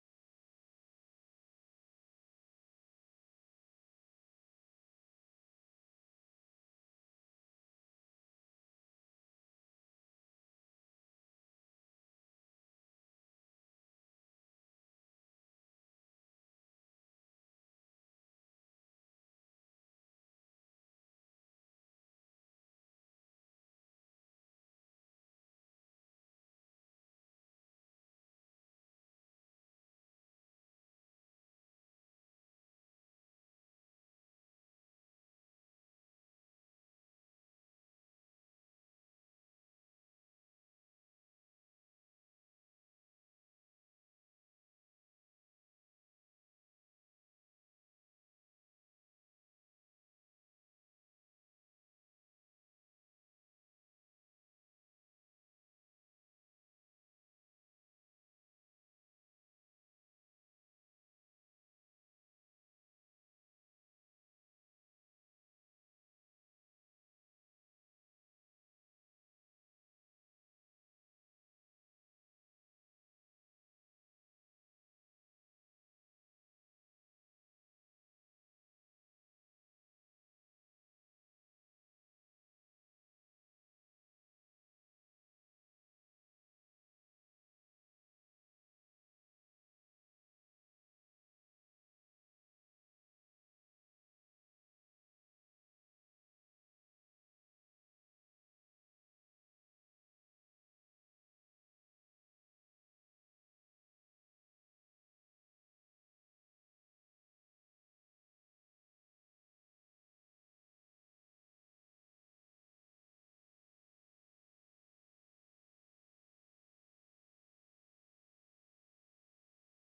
15 ottobre 2025 I cittadini che desiderano partecipare alla seduta di Consiglio del 15 ottobre 2025 alle ore 19:00 presso la sede del Municipio 7, in via Anselmo da Baggio 55, devono...